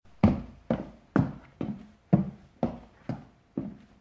shagi_lestnica.ogg